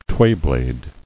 [tway·blade]